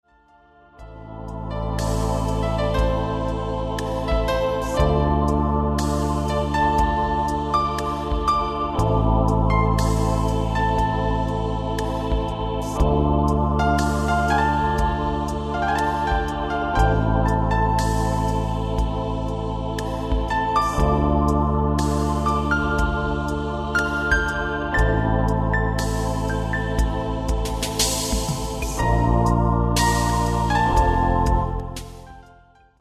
Jest prosta, melodyjna i z przyjemnością odbierana.